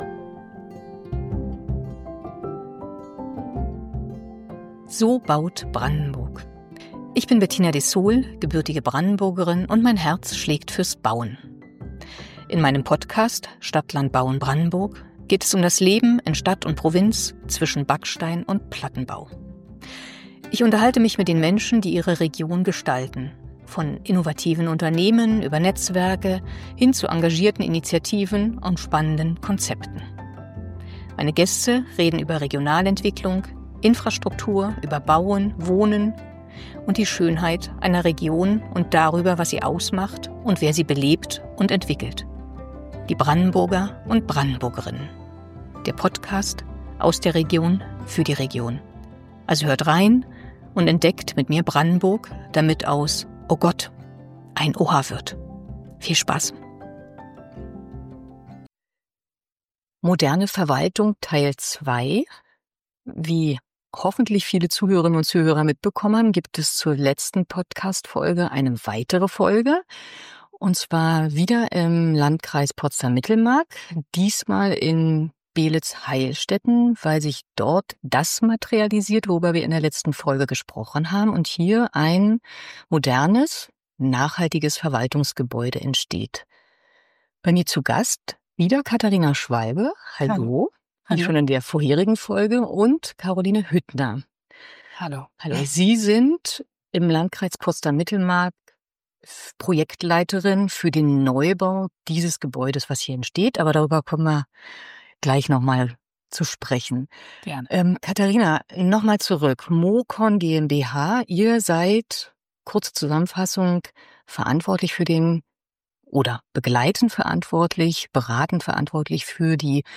Ein Gespräch über Gebäude, die mehr sind als Beton und Technik – sie sind Ausdruck einer Haltung - und über ein Projekt, das zeigt: Wandel lässt sich bauen.